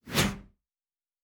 pgs/Assets/Audio/Sci-Fi Sounds/Movement/Synth Whoosh 3_3.wav at master
Synth Whoosh 3_3.wav